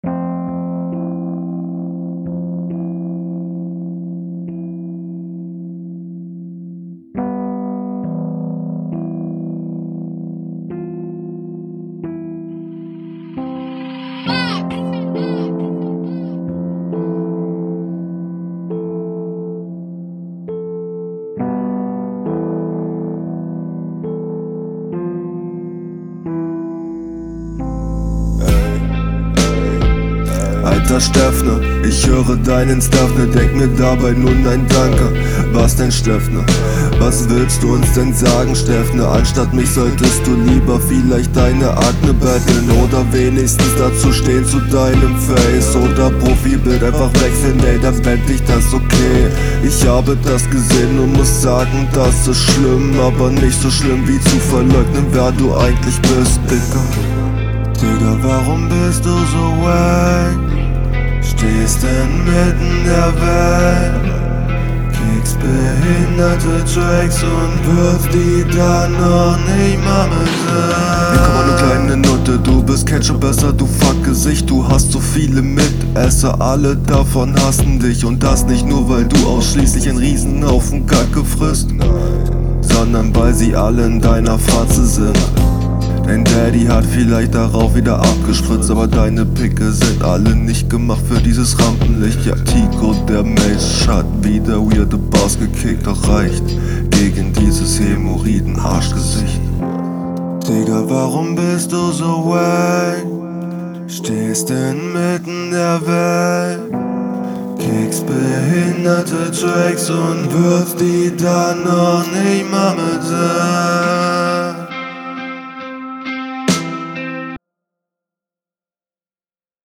Grauenvoller beat und dadurch auch ein grauenvolles Konzept. Was soll dieses Autotune-Gejaule?
mochte die spurensetzung, paar harmonien wären aber vllt noch cool gekommen mic quali suckt halt, …